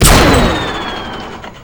gun3.wav